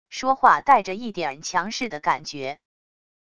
说话带着一点强势的感觉wav音频